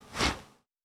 Quarterback Throw Powerful.wav